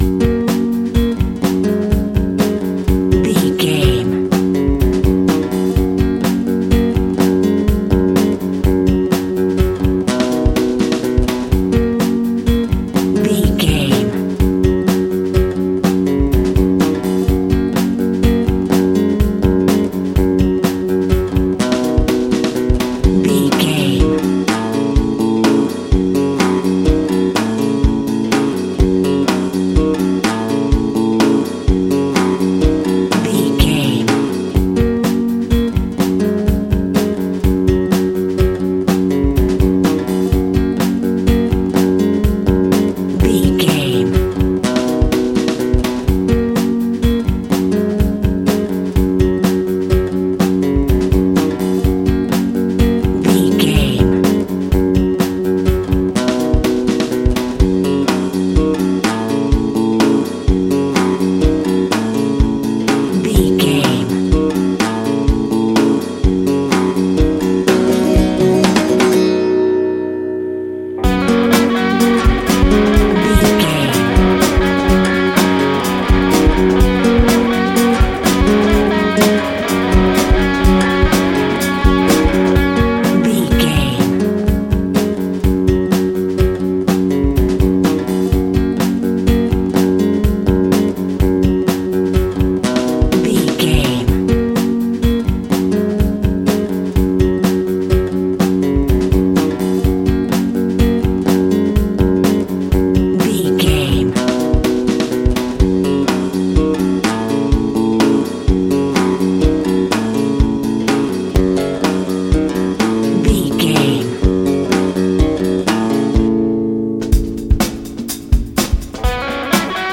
Ionian/Major
G♭
romantic
happy
acoustic guitar
bass guitar
drums